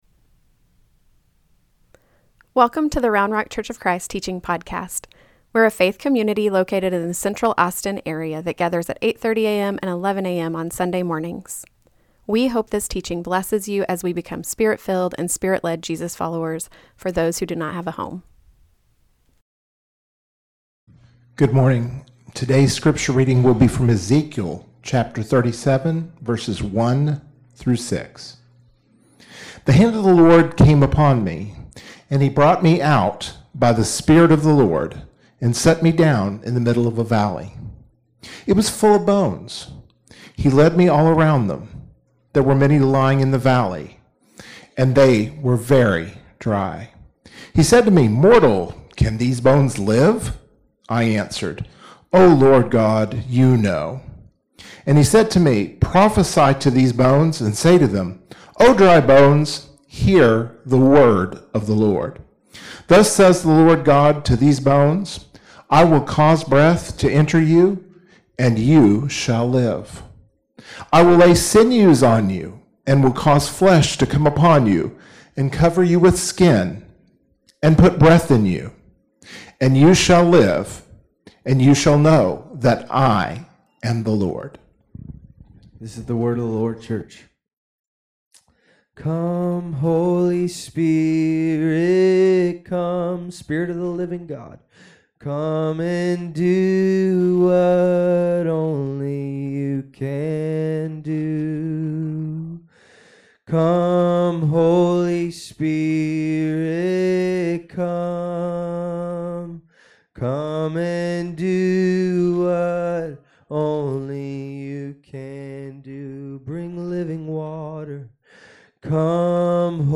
Foundations, P3: Holy Spirit: The Breath Of God Sermon Podcast - Round Rock Church Of Christ podcast